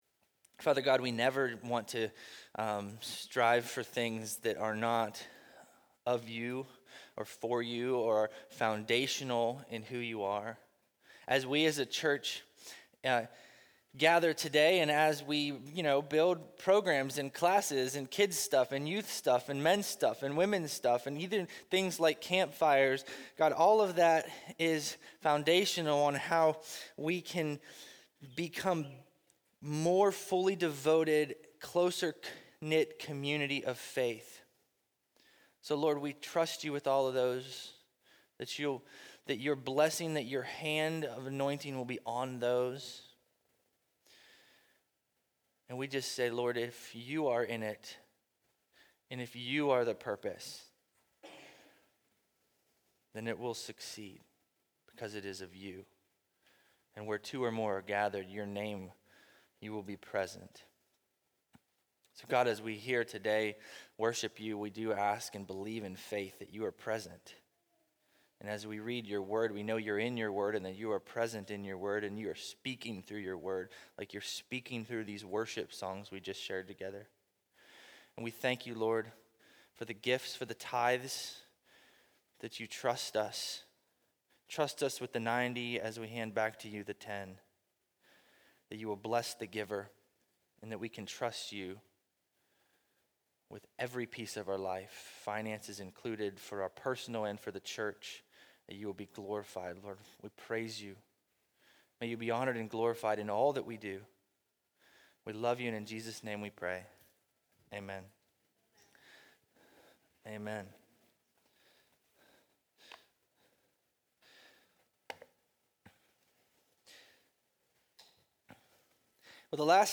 Bible Text: Jonah 1:1-16 | Preacher